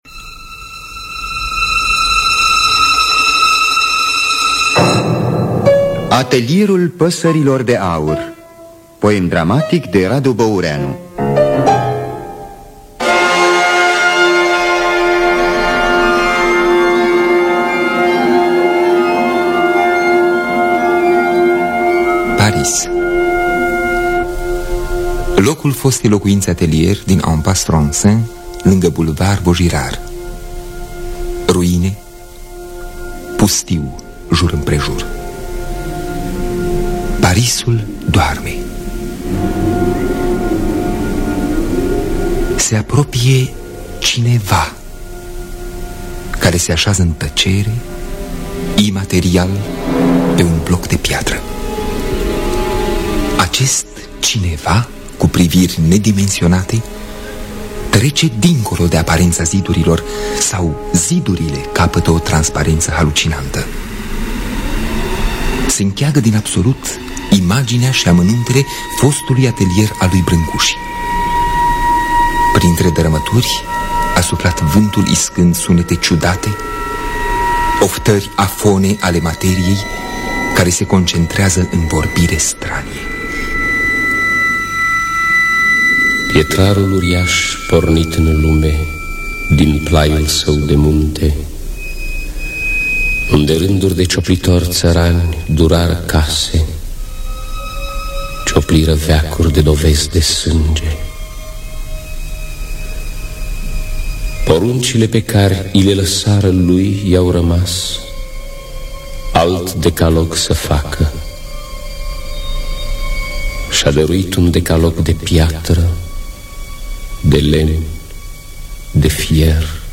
Radu Boureanu – Atelierul Pasarilor De Aur (1970) – Teatru Radiofonic Online